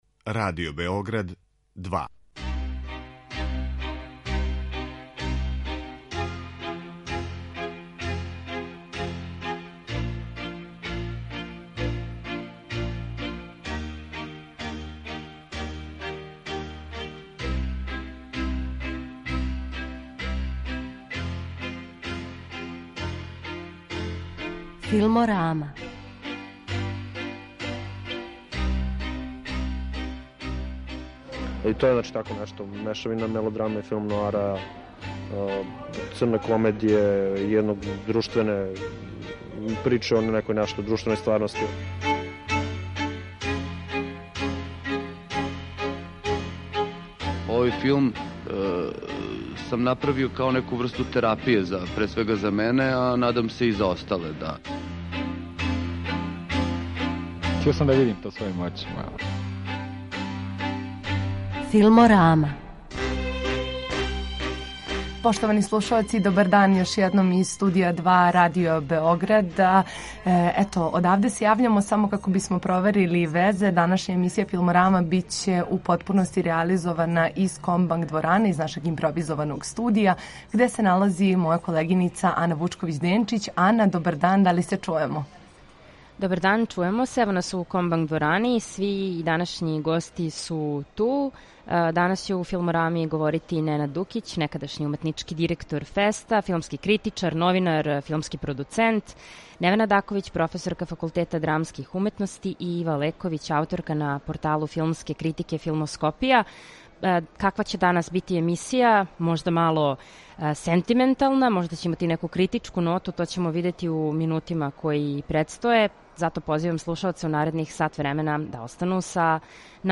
Данашња емисија биће реализована са централног места дешавања јубиларног ФЕСТ-а, места одакле је све почело пре пола века - из Дома Синдиката, сада Kомбанк дворане.